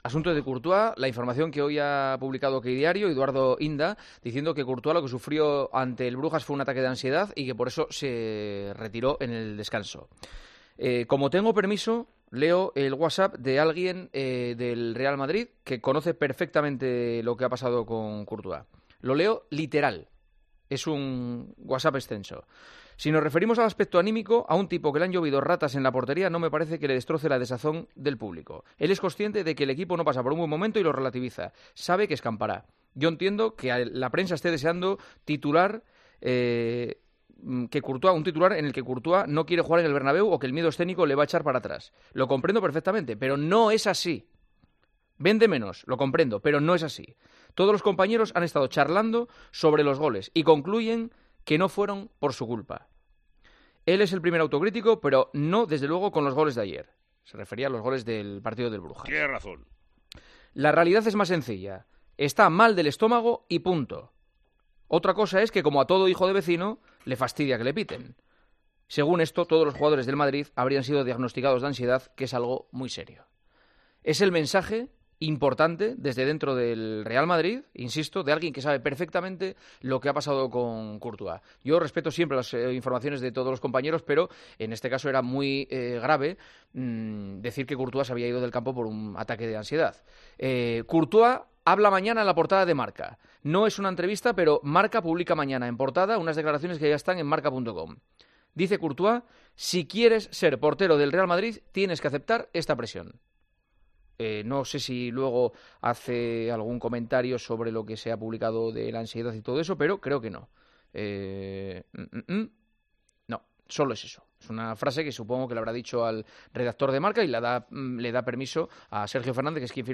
Juanma Castaño leyó un mensaje que aclaraba que Courtois no sufre de ansiedad.